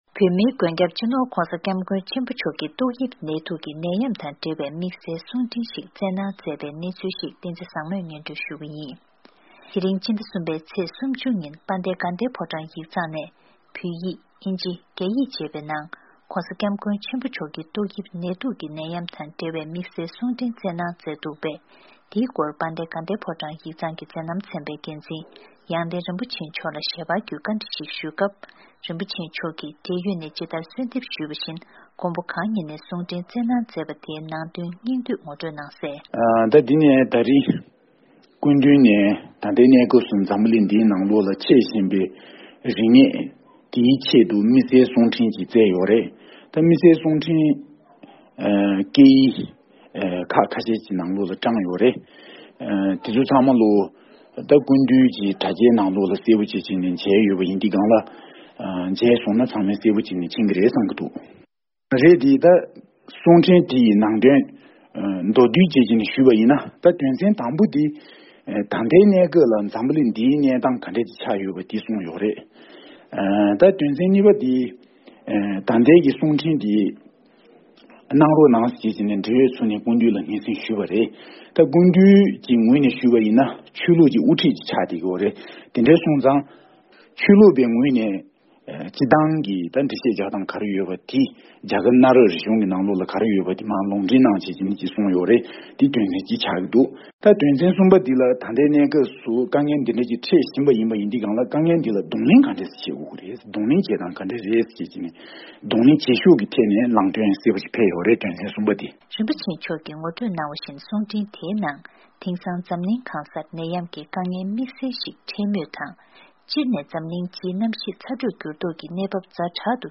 ཞལ་པར་བརྒྱུད་ནས་གསུང་འཕྲིན་གྱི་ནང་དོན་སོགས་ལ་བཀའ་དྲི་ཞིག་ཞུས་པའི་གནས་ཚུལ་ཞིག